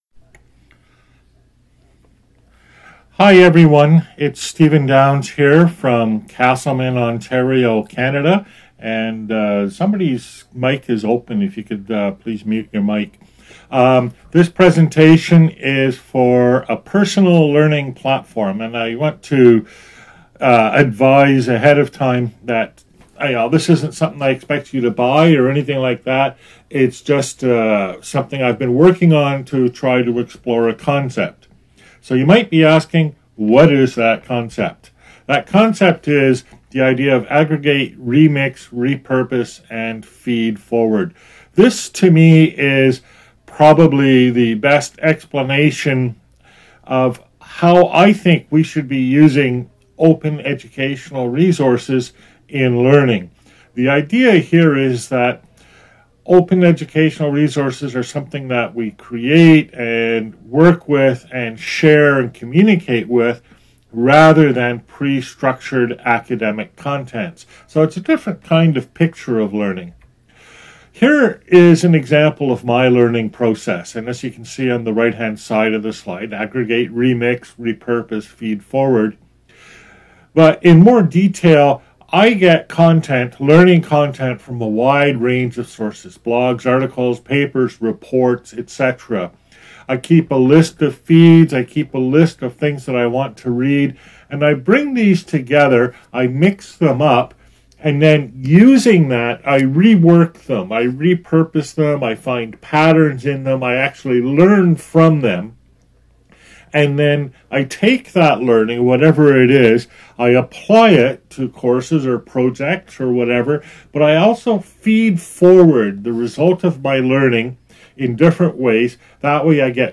Lightning Talks, Online, via Jitsi, Lecture, Dec 11, 2020.